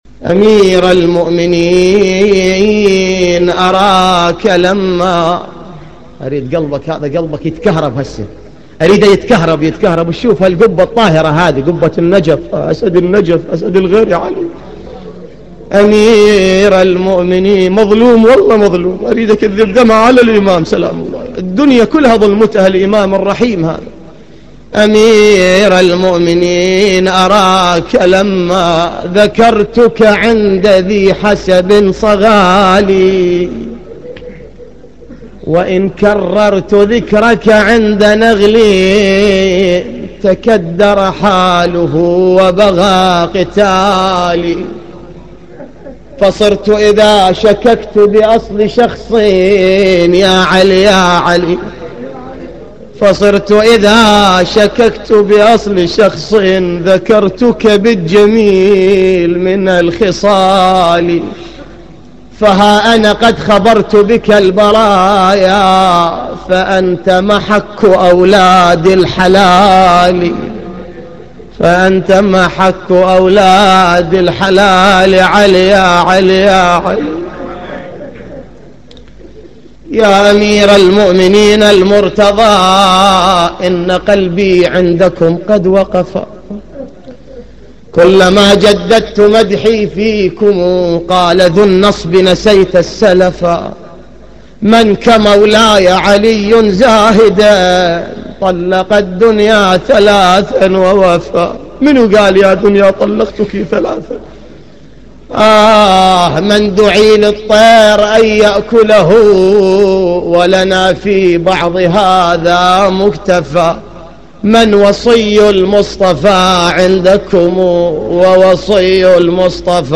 للتحميل This entry was posted in نواعي